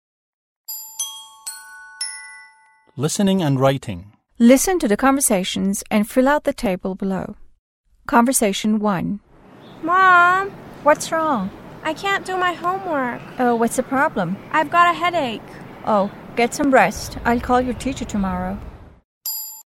مکالمه ی اول - متن لیسنینگ listening هشتم
مکالمه ی دوم - متن لیسنینگ listening هشتم